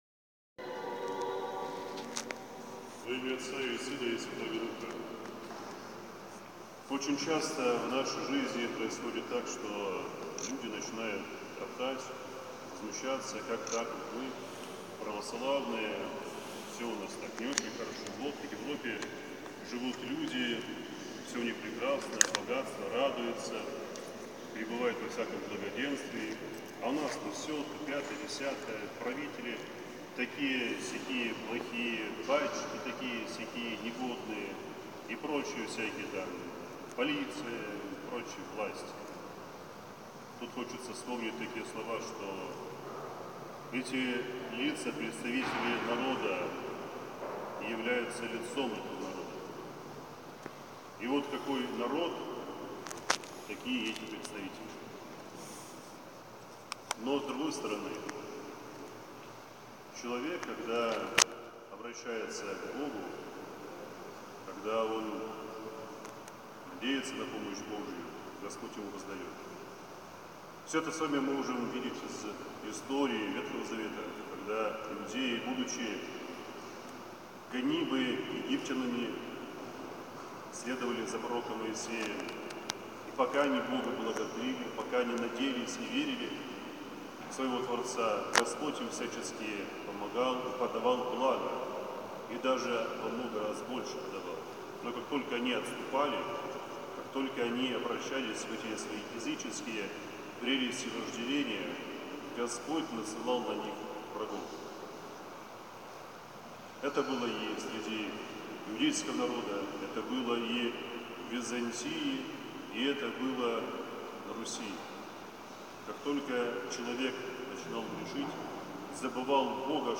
Силы тьмы всегда будут пытаться заставить христианина отойти от Бога. Через скорби, болезни и другие испытания человек должен стать крепче духовно и не бояться натиска врага. Помощницей в спасительном деле для нас является Матерь Божия, Которая творит чудеса для укрепления нашей веры в Господа.